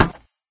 woodmetal.ogg